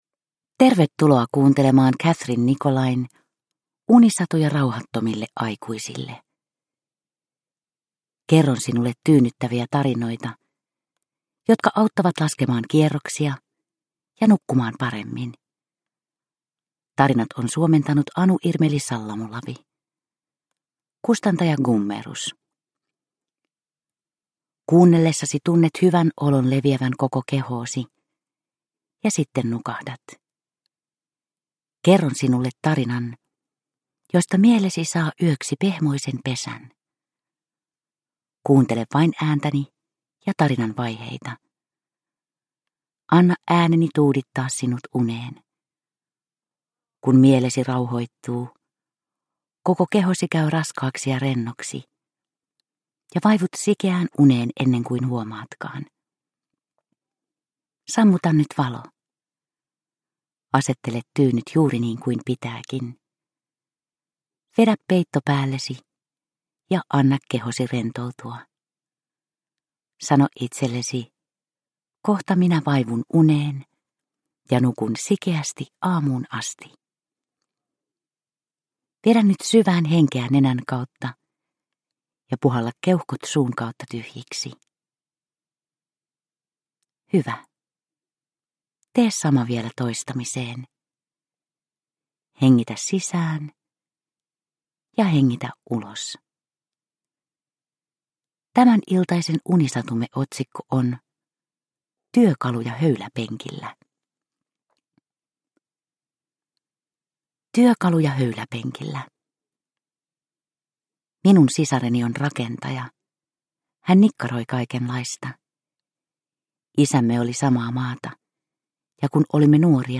Unisatuja rauhattomille aikuisille 4 - Työkaluja höyläpenkillä – Ljudbok – Laddas ner